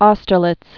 stər-lĭts, oustər-)